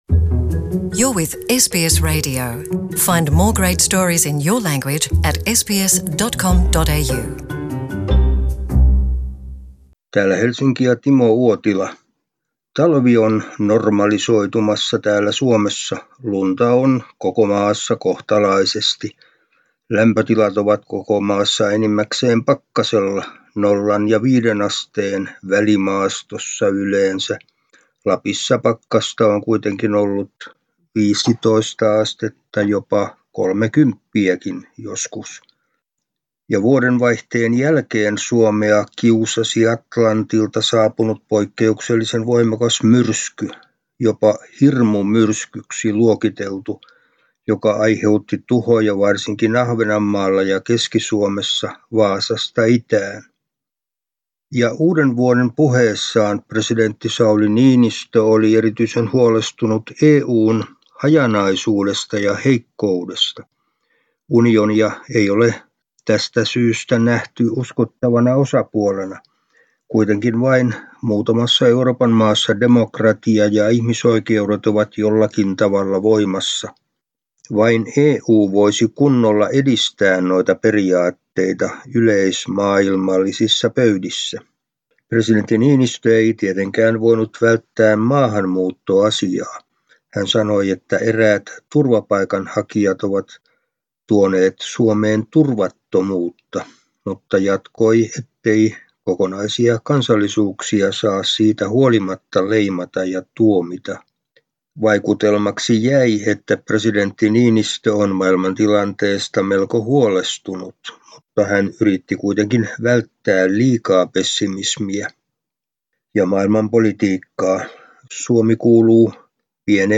ajankohtaisraportti